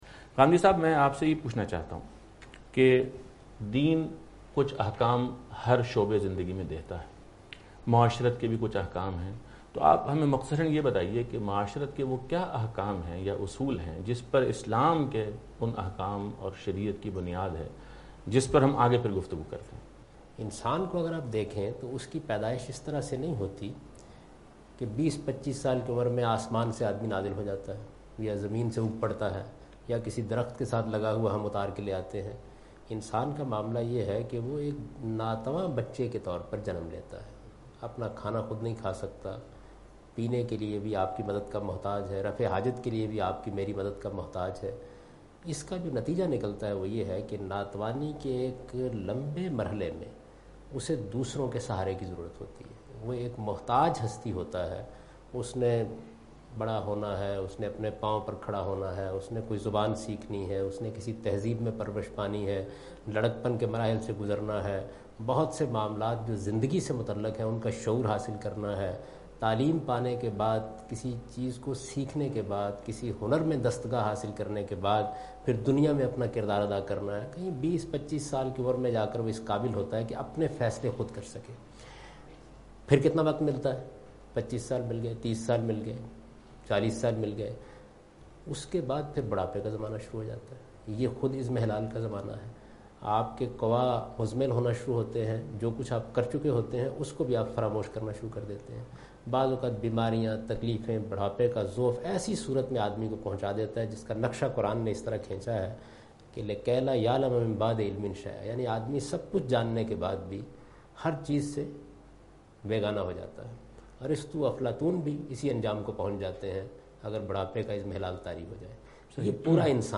Question and Answers with Javed Ahmad Ghamidi in urdu
جاوید احمد غامدی کے ساتھ اردو سوال و جواب کی ایک نشست